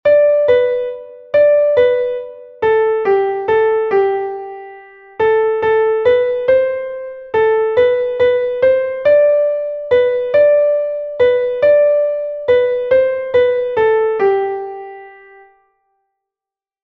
„Kuckuck, Kuckuck, ruft’s aus dem Wald“ ist ein beliebtes Kinderlied, dessen Text auf den bekannten Dichter Hoffmann von Fallersleben (1798–1874) zurückgeht.
Einstimmige Melodie im Violinschlüssel, G-Dur, 3/4-Takt, mit der 1. Strophe des Liedtextes.
kuckuck-kuckuck-rufts-aus-dem-wald_klavier_melodiemeister.mp3